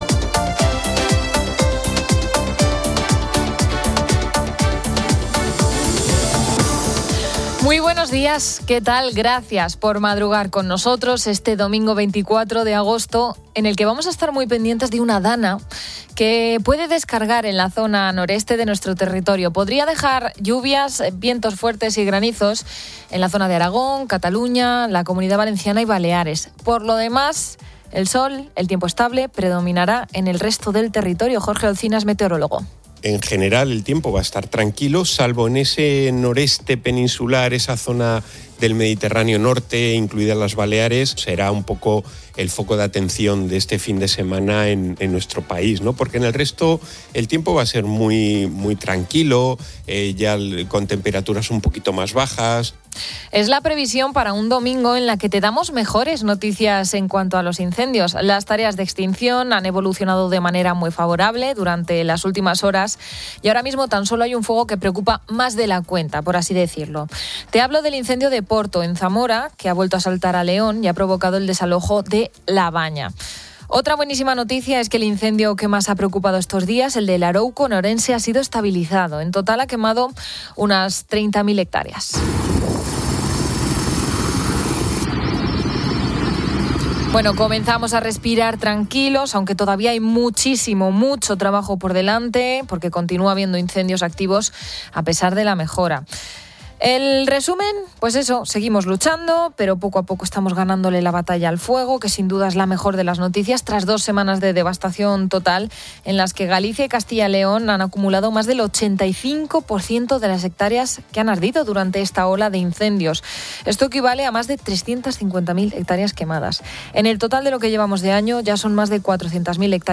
El audio presenta un resumen de noticias variadas. En cuanto al tiempo, se prevé una DANA con lluvias, vientos fuertes y granizo en el noreste peninsular (Aragón, Cataluña, Comunidad Valenciana, Baleares), mientras que el resto del país tendrá un clima estable. La situación de los incendios forestales muestra una mejora general, con varios focos estabilizados, aunque aún quedan activos.